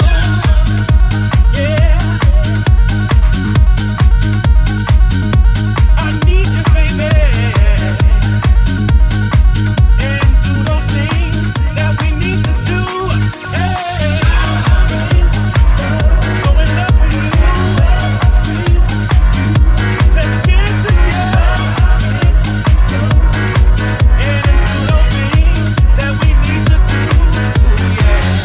Genere: pop,deep,dance,disco,news